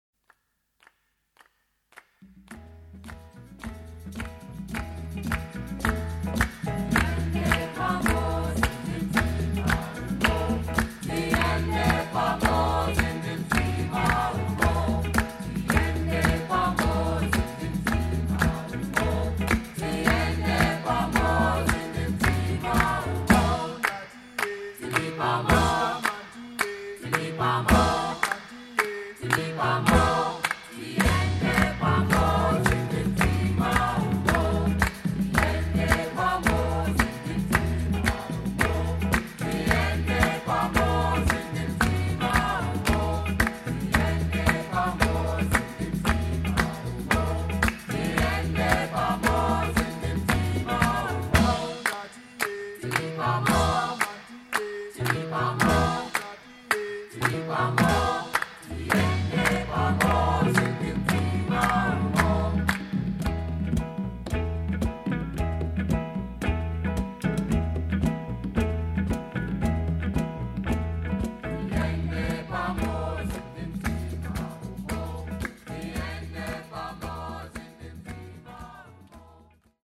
Afro、Funk、Jazz、Calypso、Mentなど様々な音楽を消化したオリジナリティ溢れる傑作。